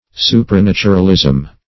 Supranaturalism \Su`pra*nat"u*ral*ism\, n.